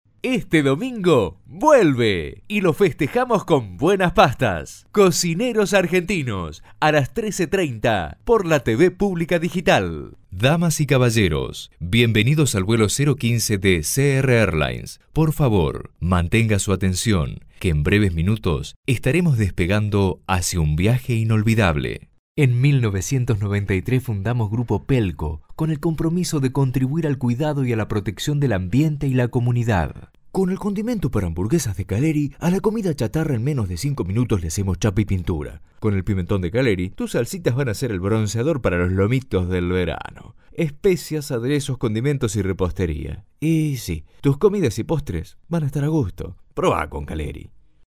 spanisch Südamerika
Sprechprobe: Werbung (Muttersprache):
voice over spanish.